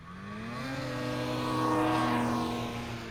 Snowmobile Description Form (PDF)
Subjective Noise Event Audio File - Run 4 (WAV)